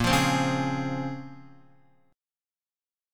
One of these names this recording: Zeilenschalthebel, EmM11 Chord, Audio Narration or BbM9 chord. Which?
BbM9 chord